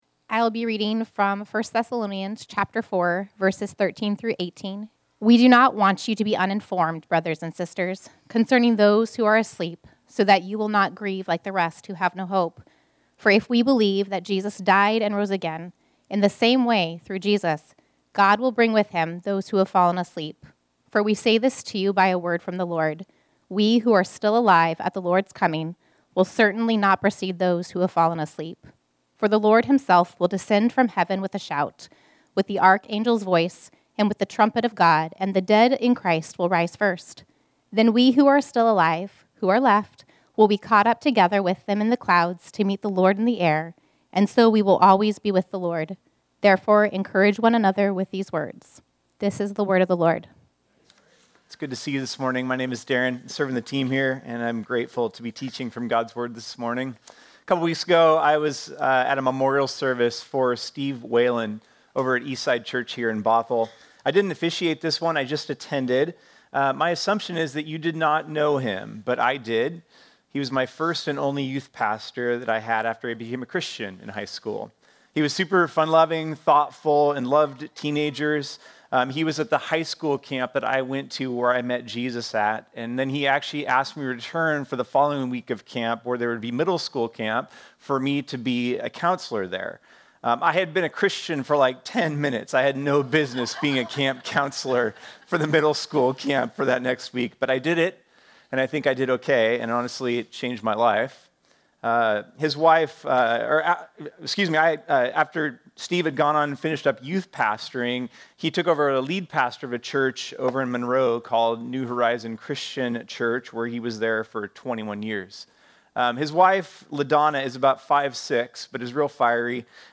This sermon was originally preached on Sunday, August 3, 2025.